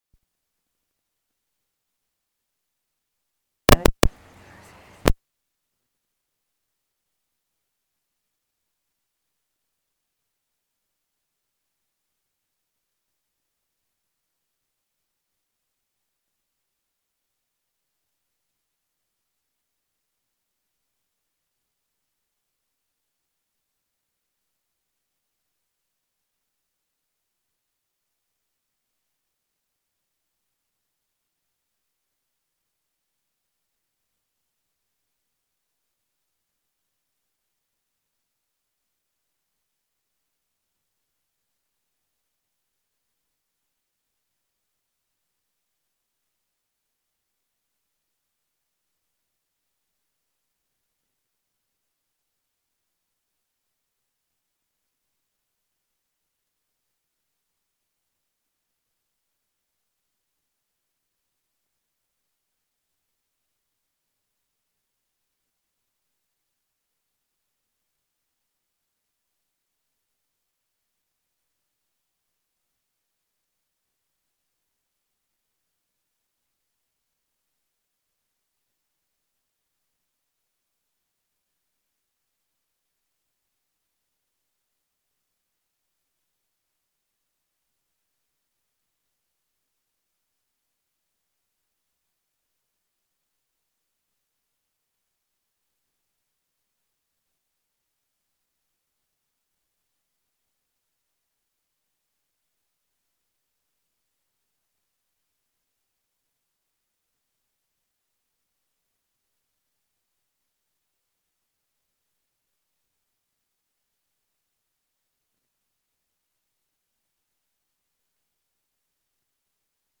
שיחת דהרמה
Dharma type: Dharma Talks שפת ההקלטה